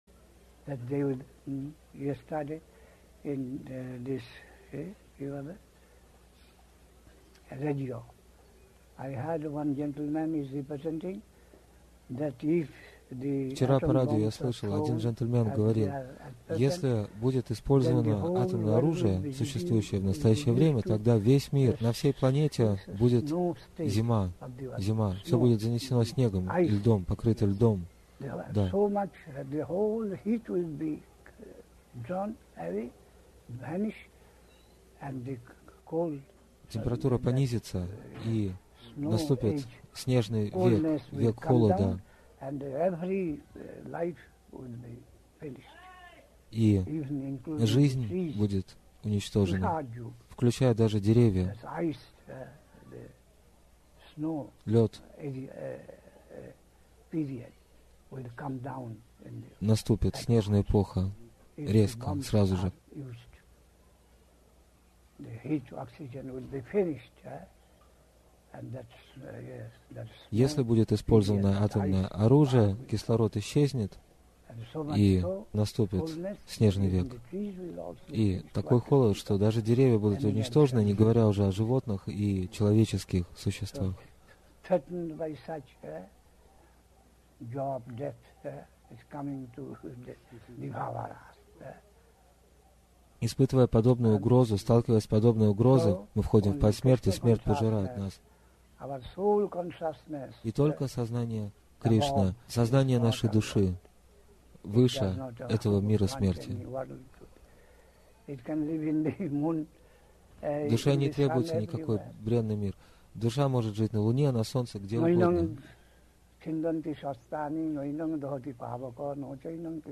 (8 января 1984 года. Навадвипа Дхама, Индия)